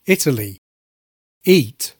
Italy begins with the short-lax KIT vowel /ɪ/. Eat on the other hand begins with the long-tense FLEECE vowel, /iː/ (often pronounced as a diphthong, ɪi or ɪj).
italy_eat.mp3